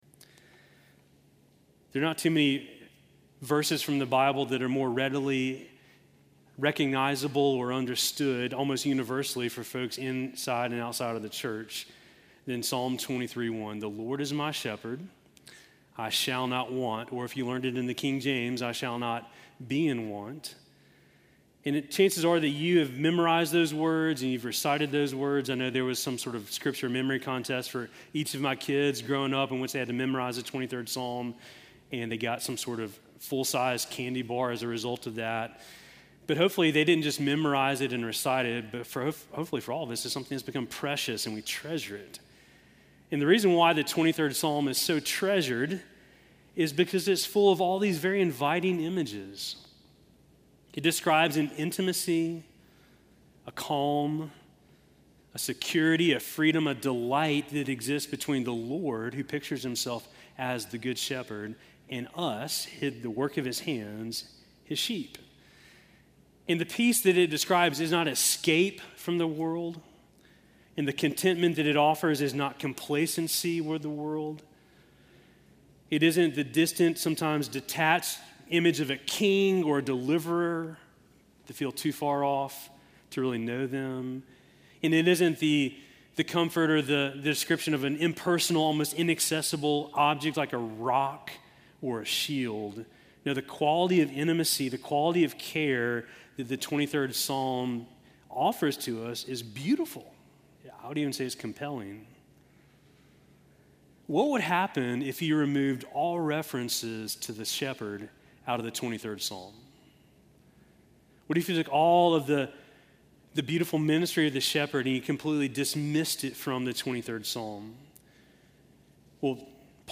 Sermon from October 19